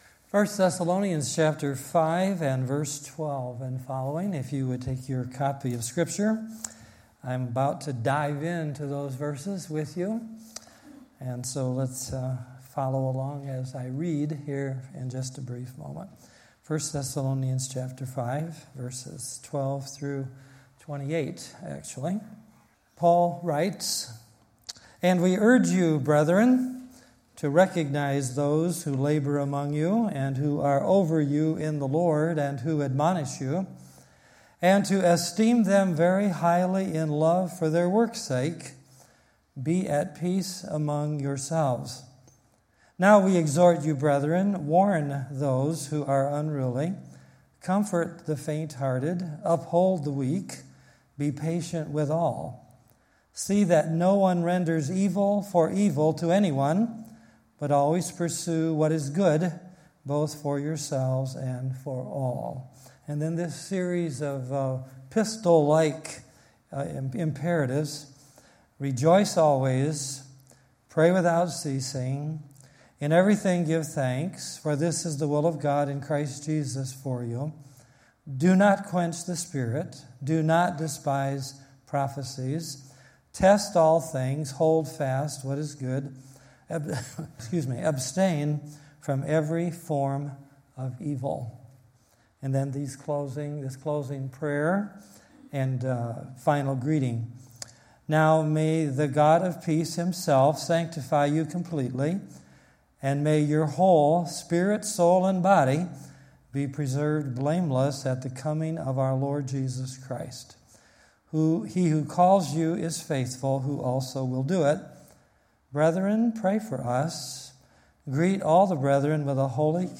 Sunday Evening Message